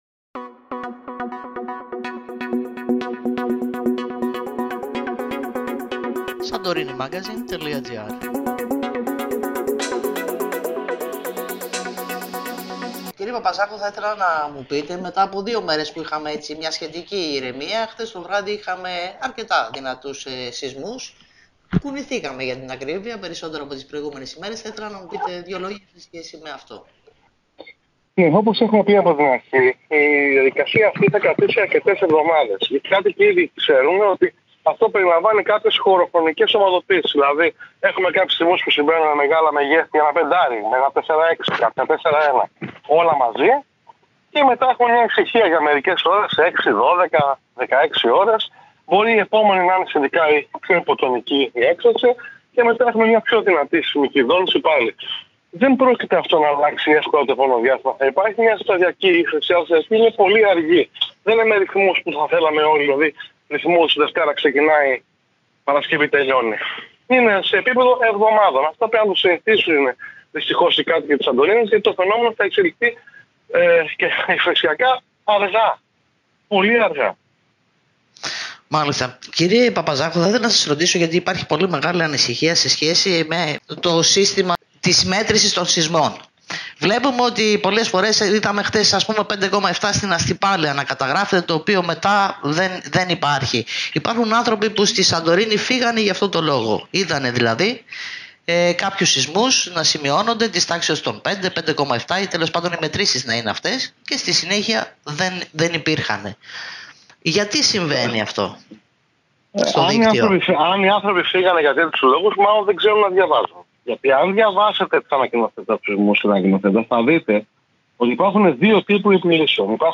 Πρέπει να συνηθίσουν οι κάτοικοι το φαινόμενο”- Συνέντευξη